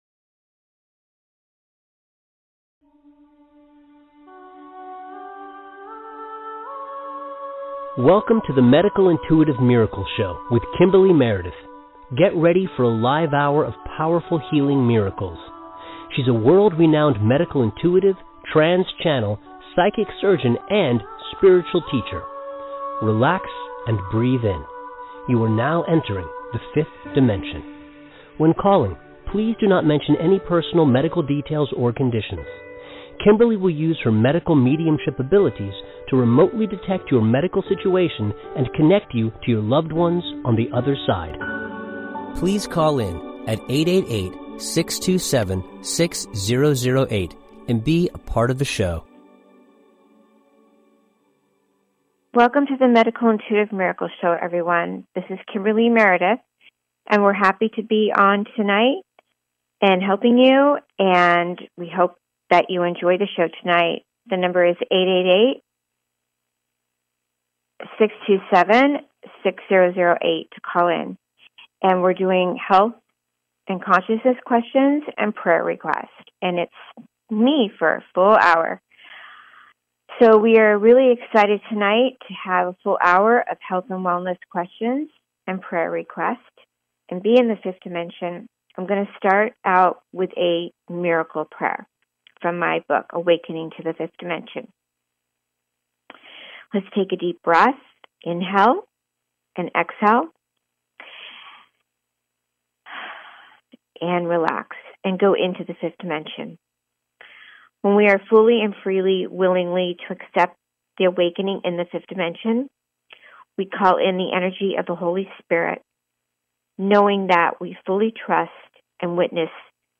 LIVE READINGS - CALL INS WELCOME